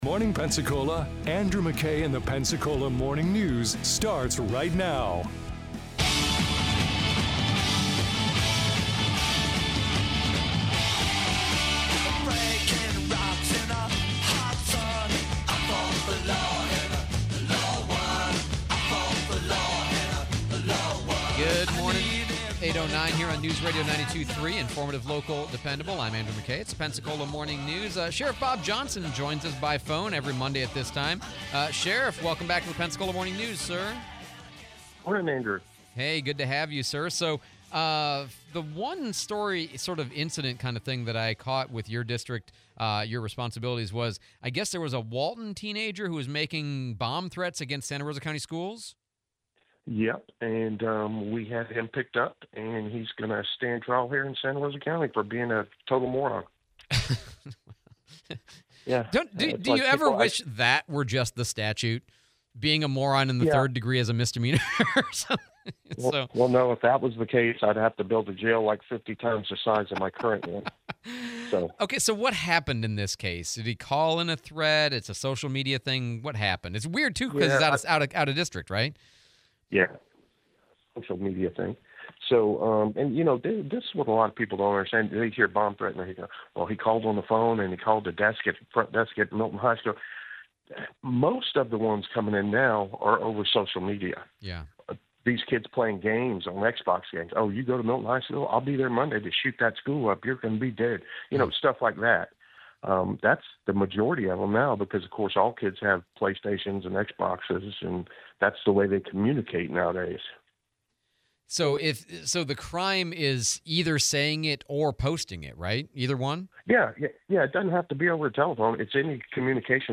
Interview w/ SRC Sheriff Bob Johnson, frivolous topic of the day, future news